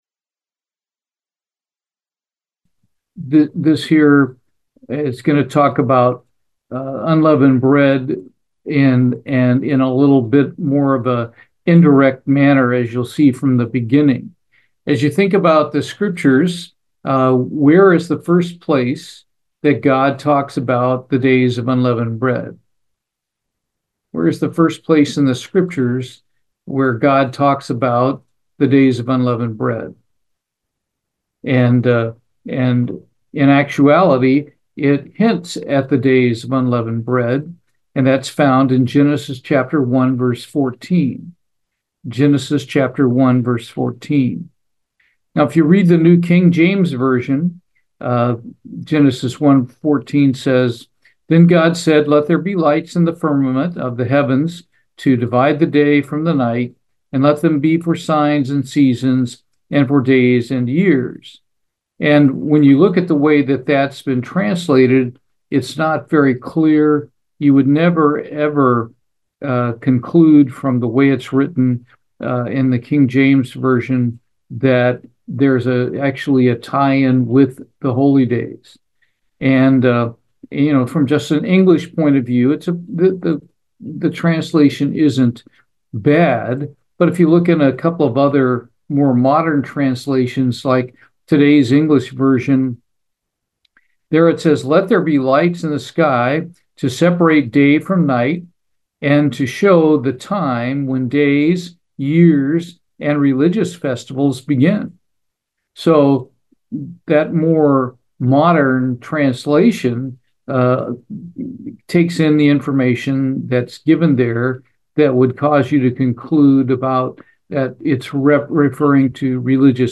Bible Study, The Hidden Days of Unleavened Bread
Given in Houston, TX